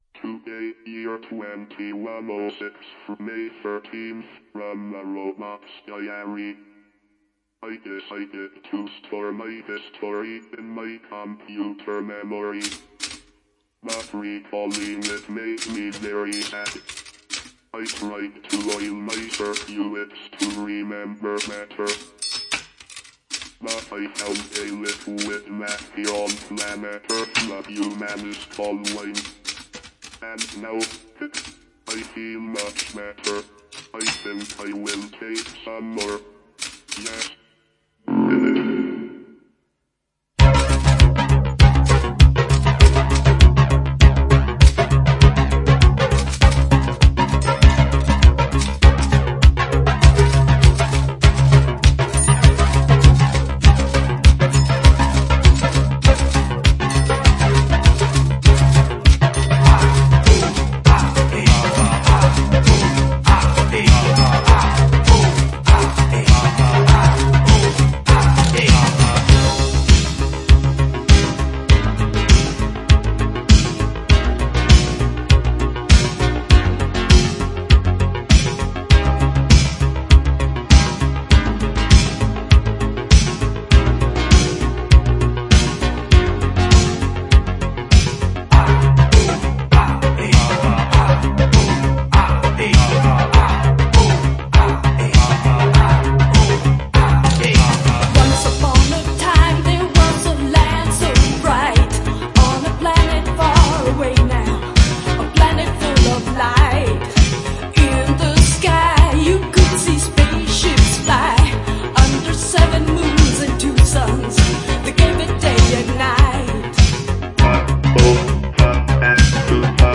Extended Mix Remastered